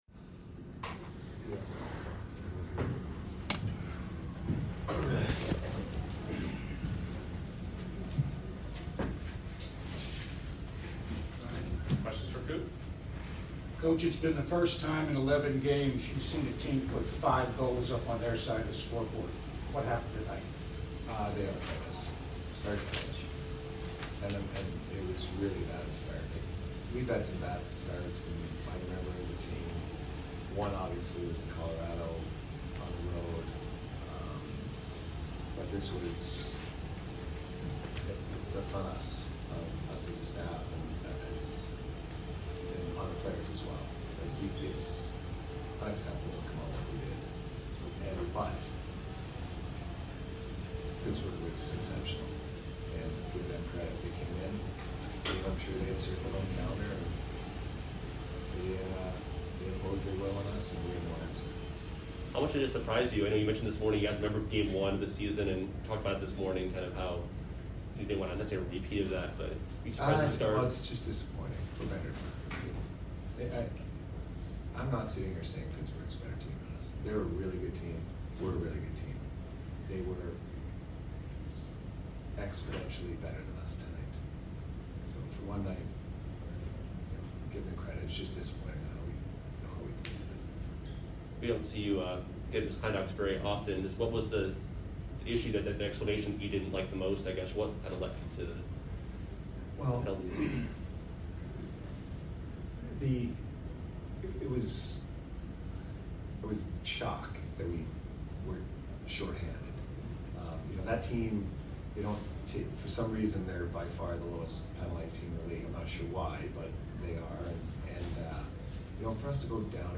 Head Coach Jon Cooper Post Game Vs PIT 3/3/2022